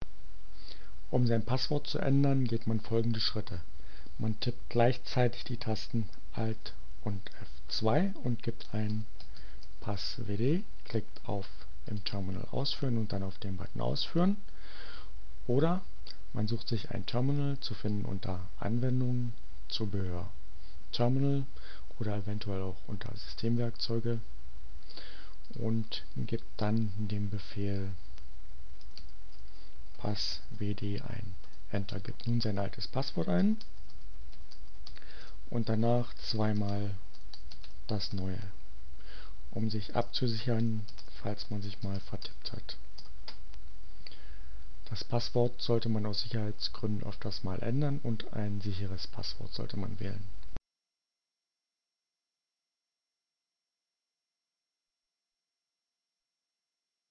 Tags: CC by-sa, Gnome, Linux, Neueinsteiger, Ogg Theora, ohne Musik, screencast, short, Sicherheit, ubuntu, passwd, Passwort